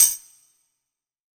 6TAMBOURIN19.wav